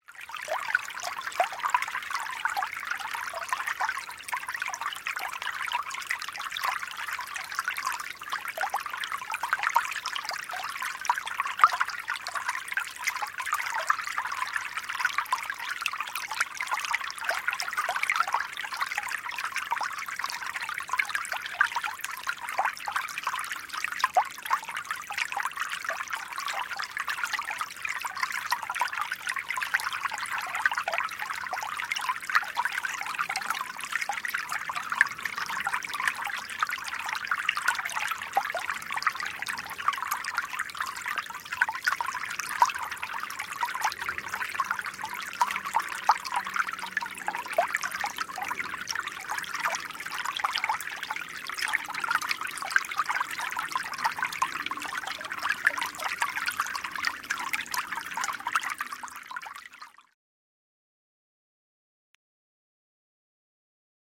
Звуки журчания ручья
Шум родниковой воды, наполняющей быстрый ручей